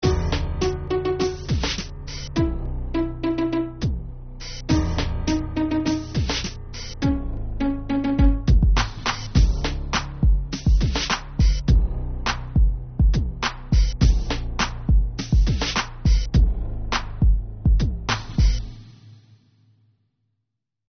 (инструментальный); темп (95); продолжительность (2:30)